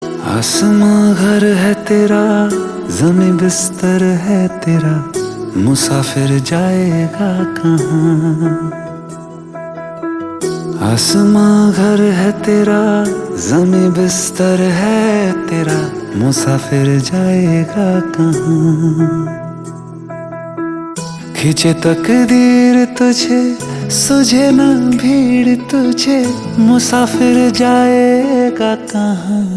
melodious